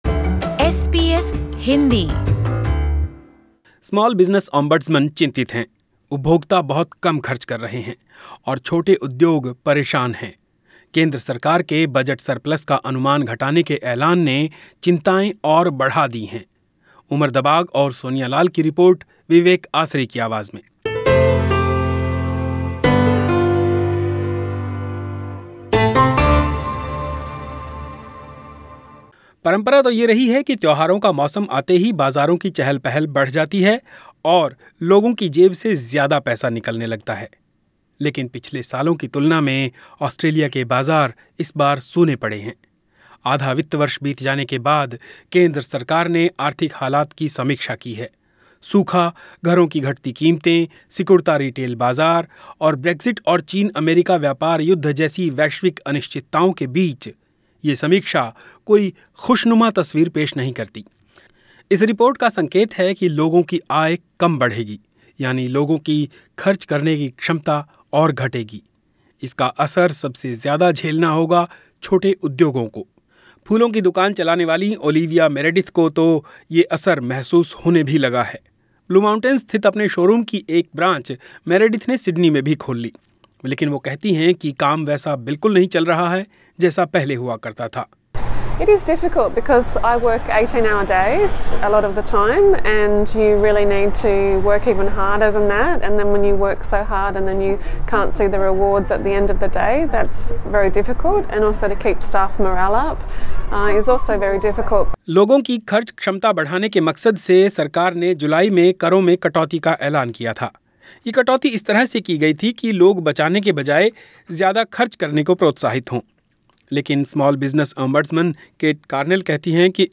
The Small Business Ombudsman is concerned consumers are not spending enough money -leaving some small businesses struggling to survive. This comes after the federal government announced it is slashing the size of its budget surplus by close to $22 billion across four years owing to a slowing economy. Listen to this report.